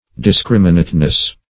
discriminateness - definition of discriminateness - synonyms, pronunciation, spelling from Free Dictionary
discriminateness.mp3